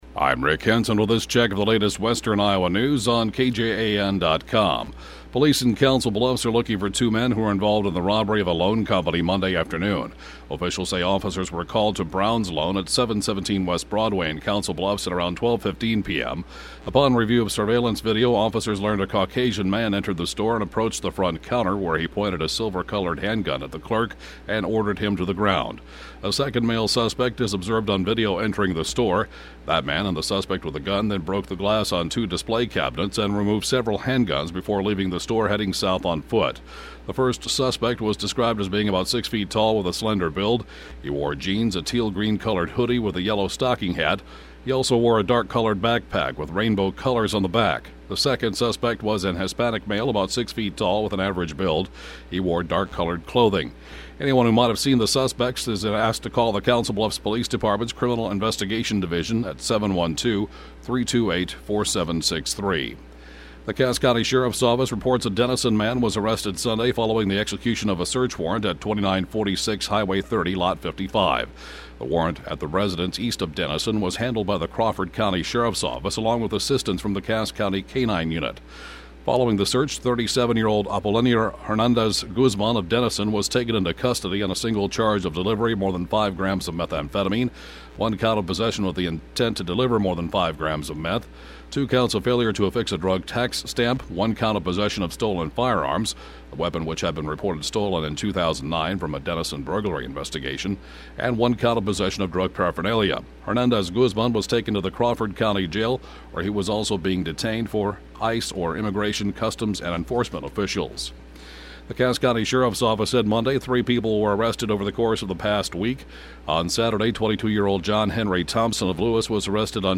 7:07-a.m. Local News (Podcast) 11/20/2012